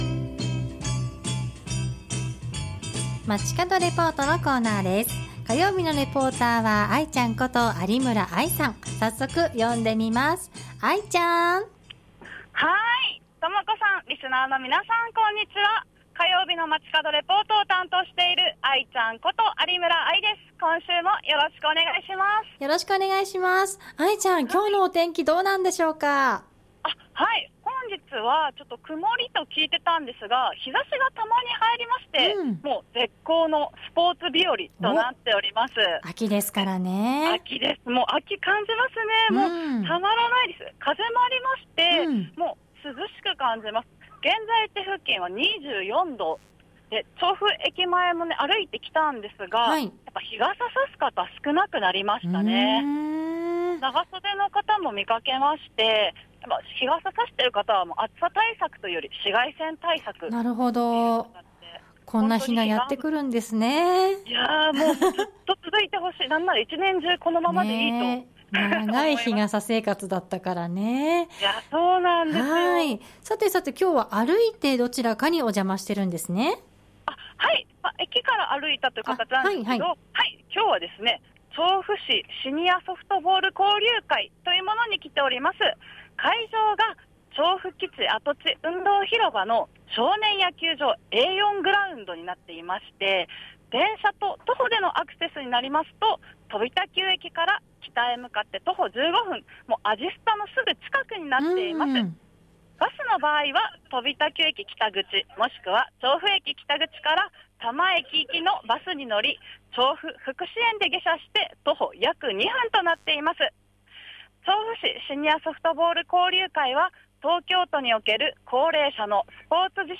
そして本日は秋分の日。スポーツの秋！ということで、調布基地跡地運動広場から「調布市シニアソフトボール交流会」についてお届けしました。
中継開始直前に開会式が始まりました。
中継の途中から1試合目が始まりましたが、審判の声や選手の掛け声、観客の声援で、会場は活気に溢れていました。